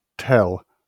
enPR: tĕl, IPA/tɛl/, SAMPA/tEl/
wymowa amerykańska?/i